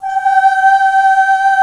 FEM 7 G4.wav